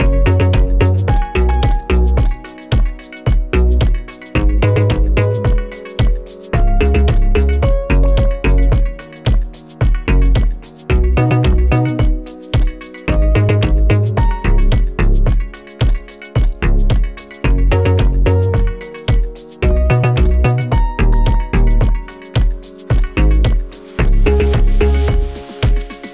Gb Major
Chill (Blue)
Slime Bass
Slime Lead
Slow Feeling